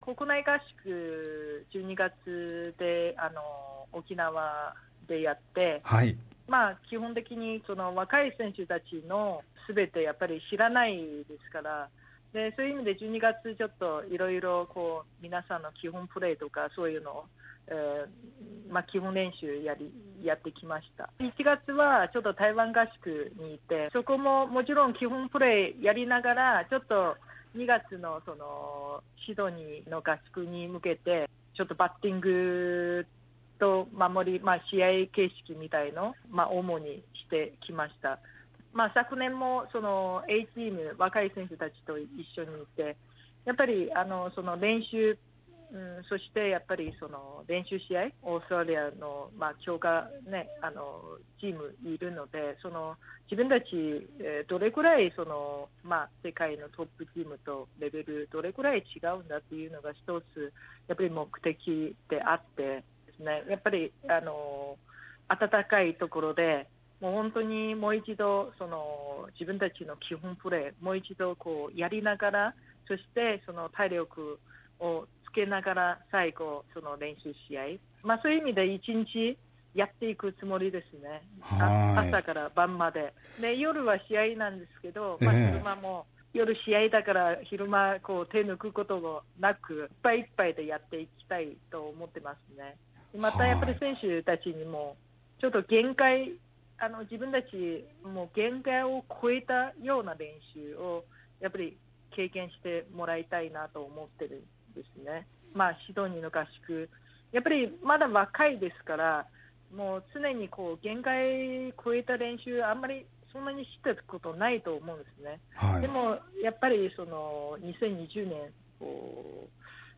2月4日から24日まで、選手団がシドニー郊外のブラックタウンに強化合宿のため訪れます。自身もシドニーオリンピックで選手として活躍した宇津木麗華ヘッドコーチに、オリンピックに向けた意気込みを聞きました。